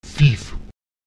Lautsprecher cic [TiT] gasförmig